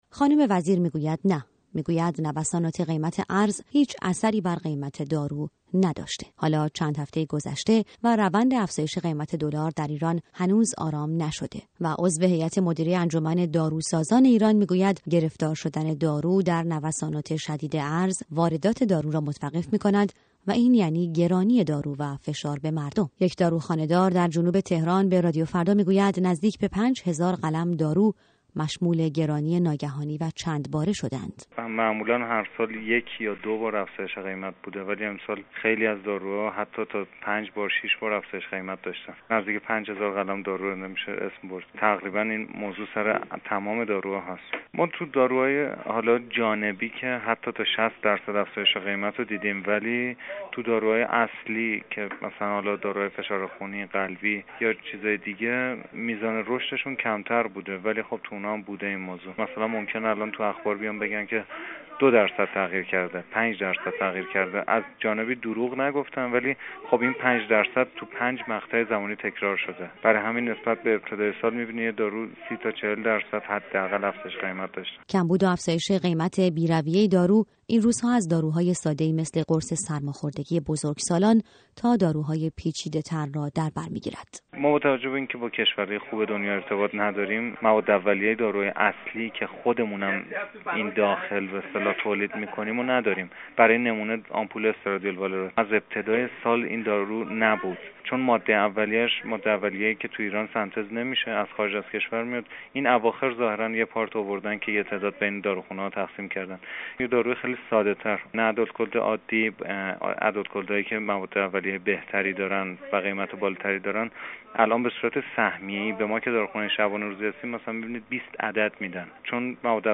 گزارش رادیویی